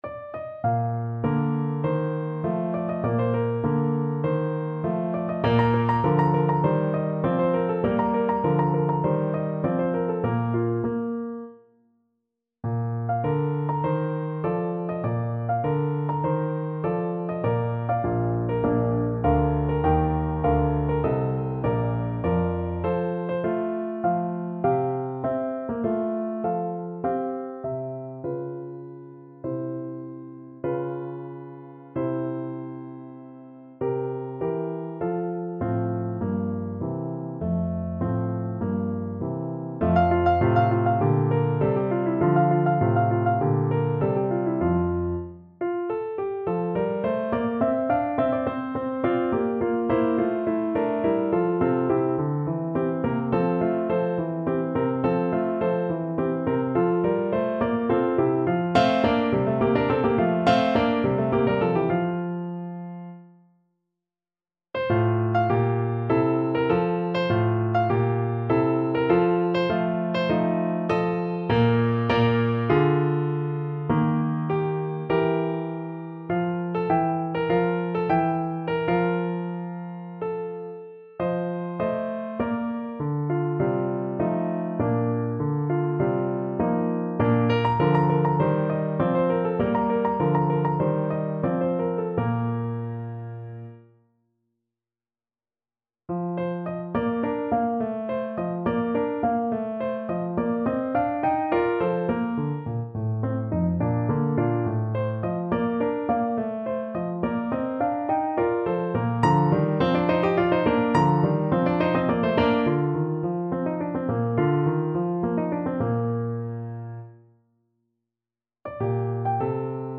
Bb major (Sounding Pitch) F major (French Horn in F) (View more Bb major Music for French Horn )
~ = 50 Larghetto
Classical (View more Classical French Horn Music)